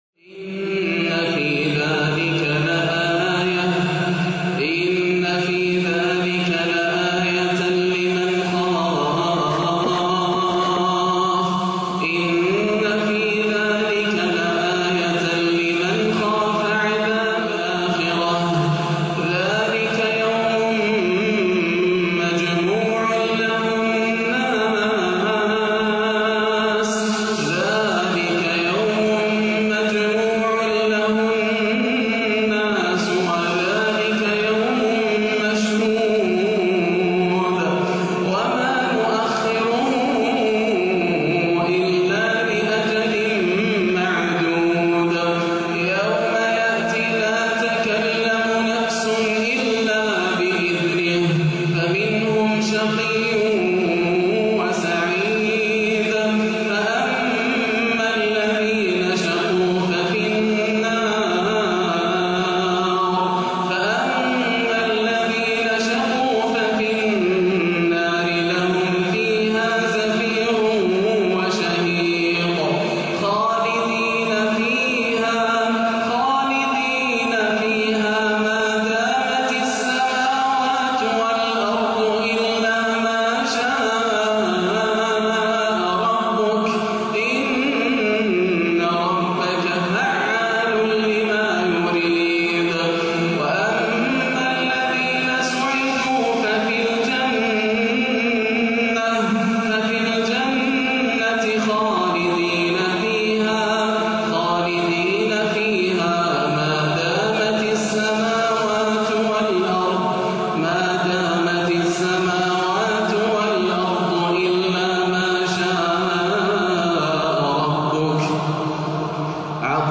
(فَمِنْهُمْ شَقِيٌّ وَسَعِيدٌ) تلاوه مؤثرة لما تيسر من سورتي هود والأحزاب - السبت 2-7 > عام 1437 > الفروض - تلاوات ياسر الدوسري